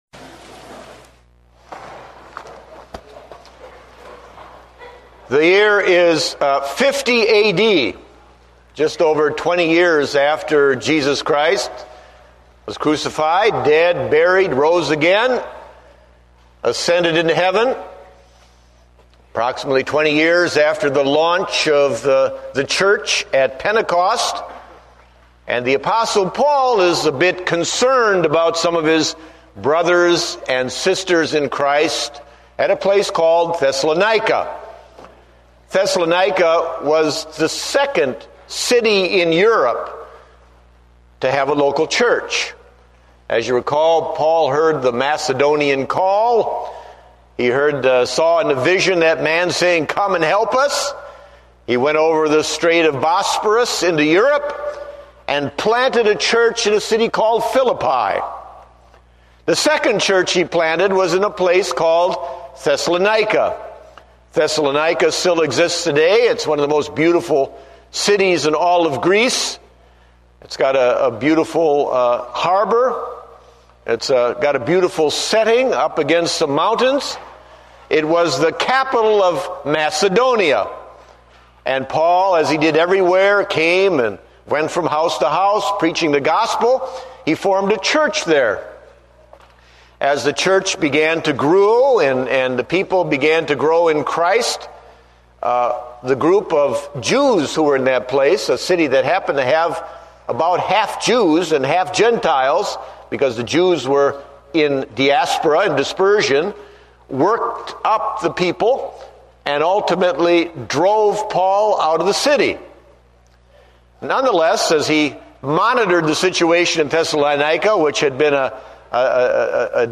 Date: October 19, 2008 (Morning Service)